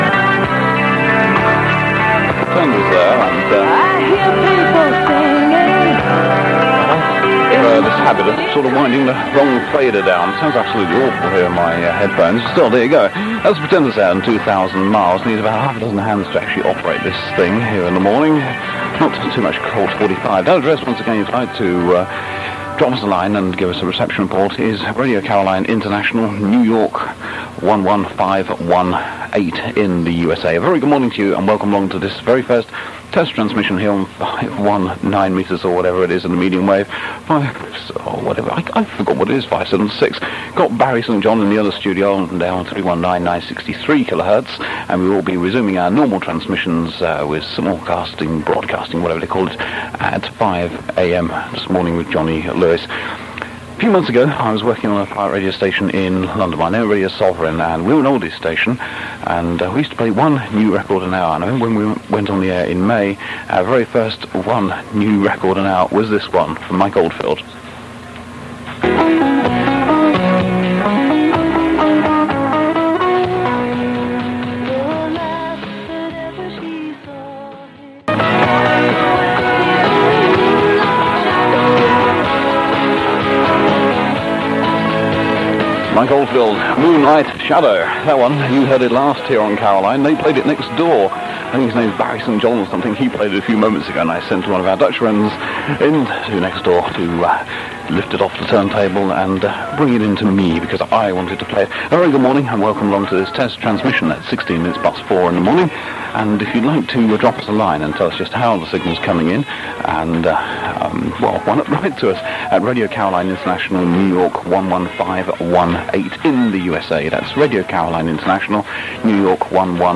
hosting a test transmission on 576kHz in the early hours of 17th October 1984. An excellent engineer but not the slickest DJ - he forgets the frequency, gets his colleague's name wrong and ‘crashes’ the vocals!